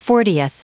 Prononciation du mot fortieth en anglais (fichier audio)
Prononciation du mot : fortieth